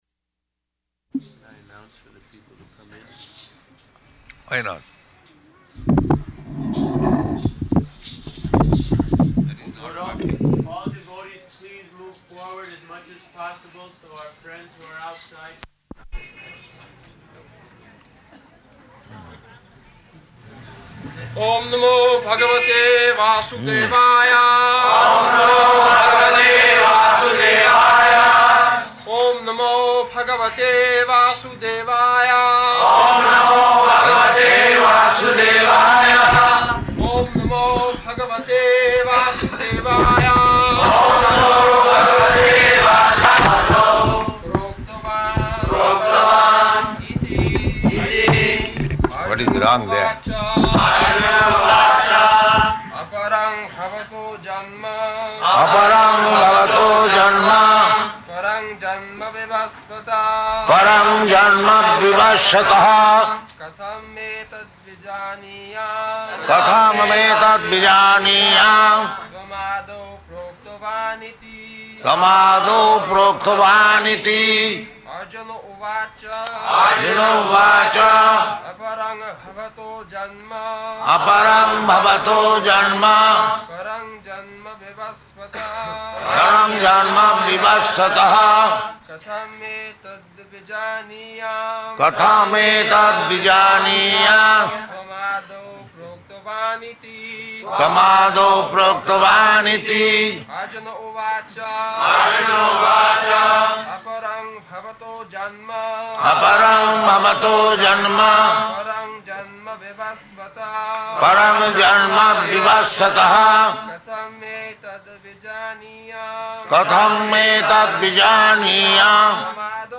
-- Type: Bhagavad-gita Dated: March 24th 1974 Location: Bombay Audio file